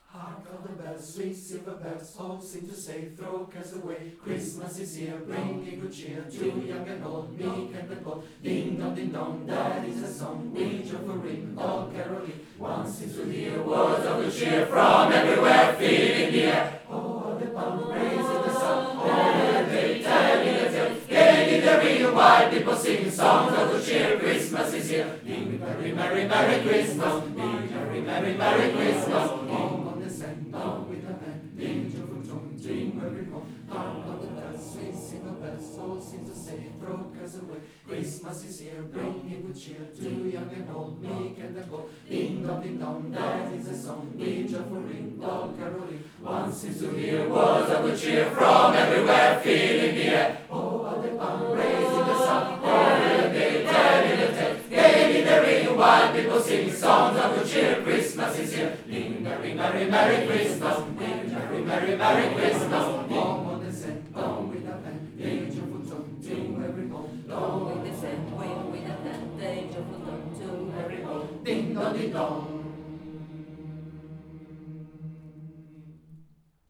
Checcoro, primo coro LGBT di Milano formato da persone di ogni orientamento sessuale, sostiene e promuove una cultura dei diritti sociali e civili per tutti